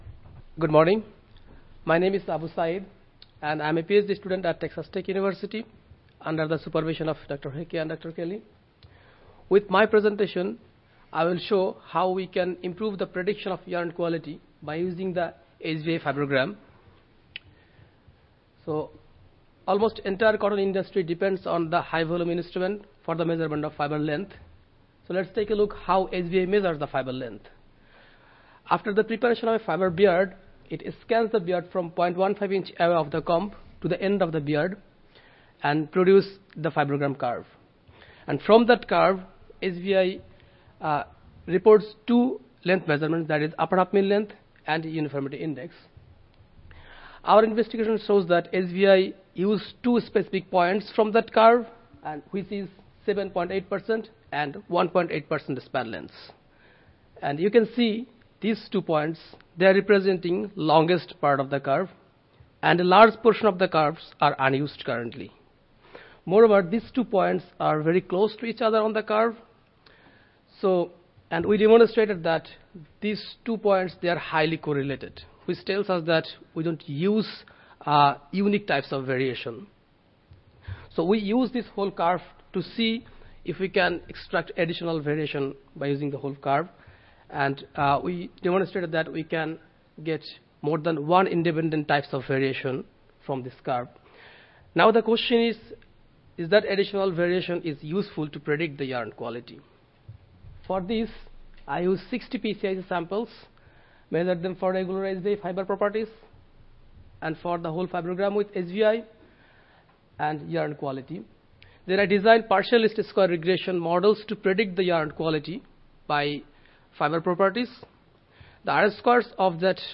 Cotton Improvement - Lightning Talks Student Competition
Audio File Recorded Presentation